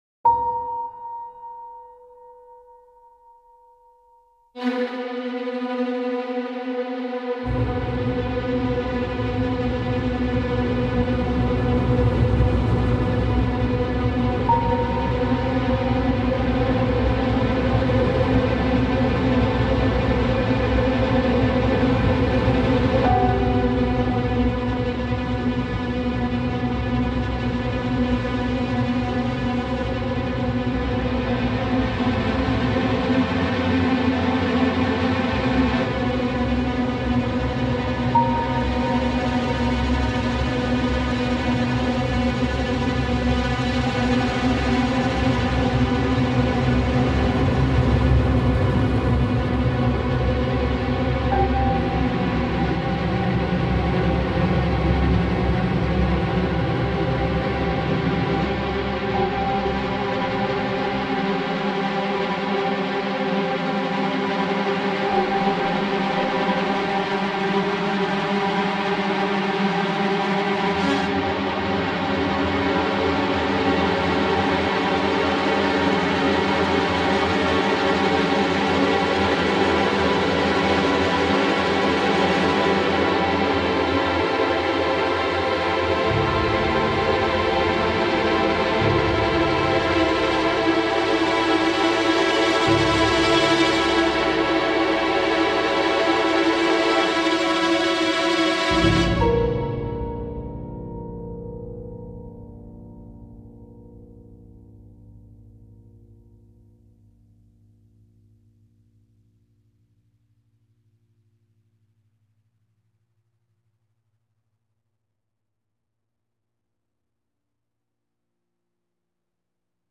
horreur - peur - angoisse